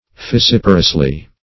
Meaning of fissiparously. fissiparously synonyms, pronunciation, spelling and more from Free Dictionary.
-- Fis*sip"a*rous*ly , adv.